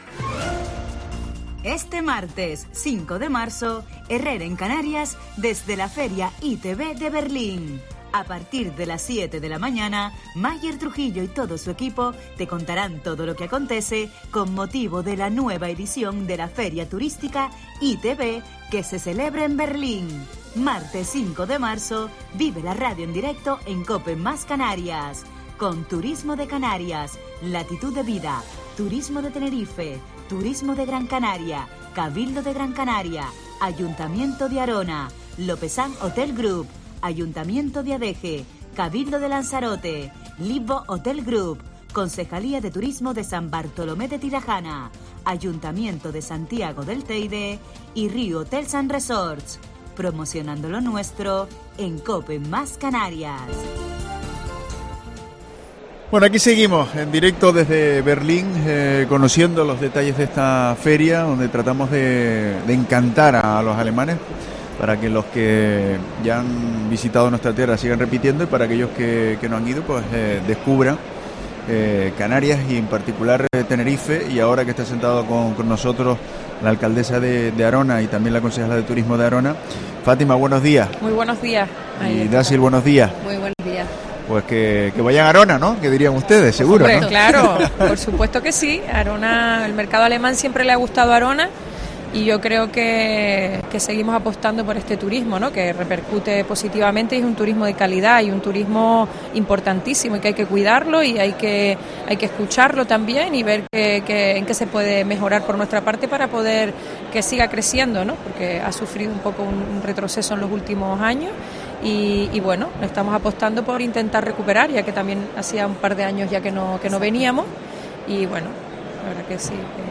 En declaraciones en Herrera en Canarias desde el programa especial que se realiza desde la ITB, Fátima Lemes manifestó la importancia de la presencia de Arona tras cuatro años de falta de promoción del municipio en Berlín: